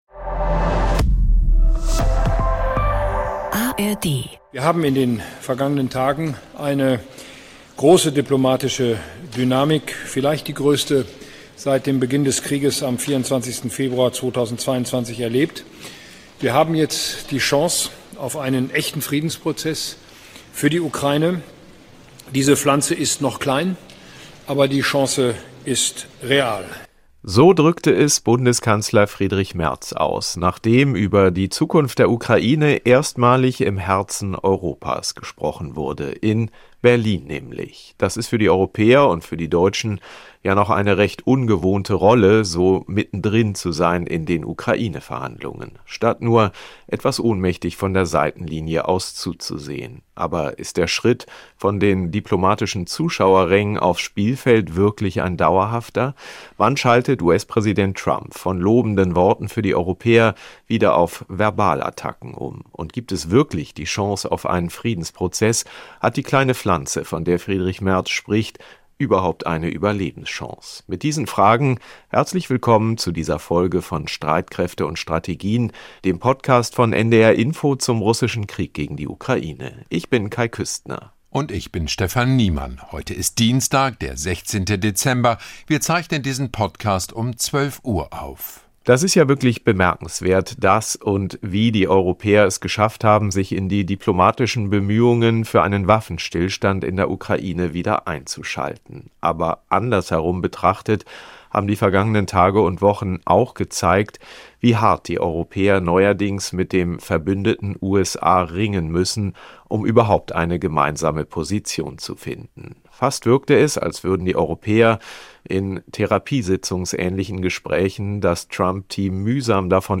Neitzel plädiert im Podcast Streitkräfte und Strategien erneut für die Einführung der Teil-Wehrpflicht und eine umfassende Reform der Bundeswehr. Mit Blick auf ein Ende des Krieges und einen möglichen Waffenstillstand zeigt sich Neitzel skeptisch.